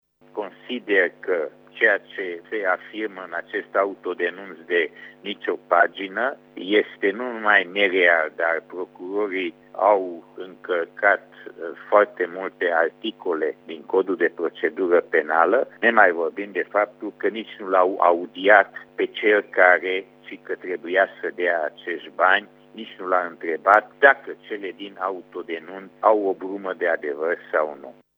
Deputatul UDMR Borbély Lászlo a declarat, după şedinţa Comisiei juridice, că prin această solicitare de începere a urmăririi sale penale s-a făcut un abuz.